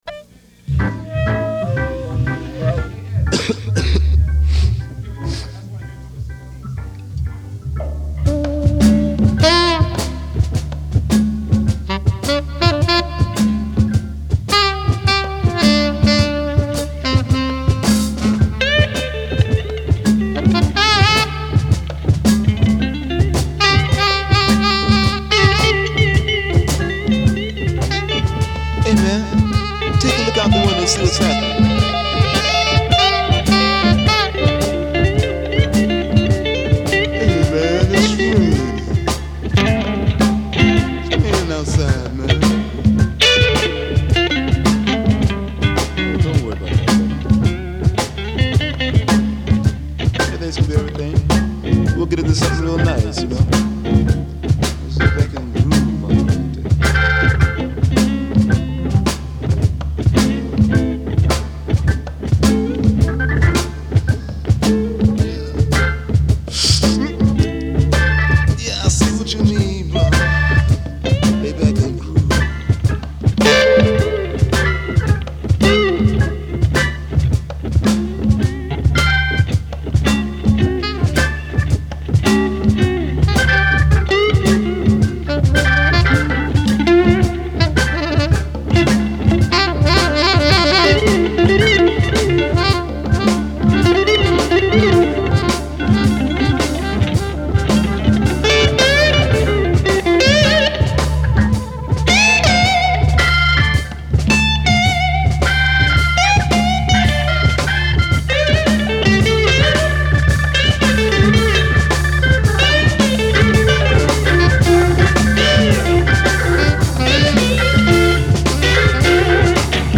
Жанр: Psychedelic, Blues Rock